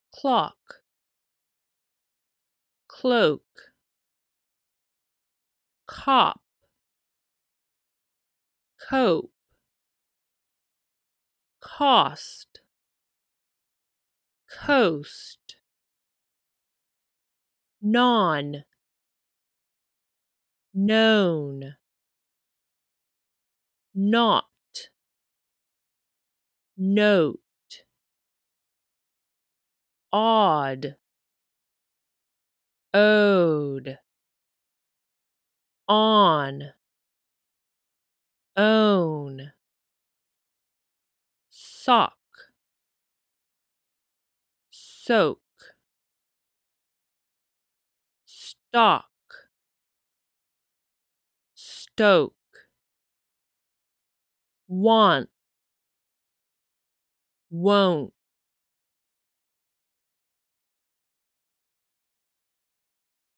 Step 4 二重母音と単母音 /ɑ/と/ɔu/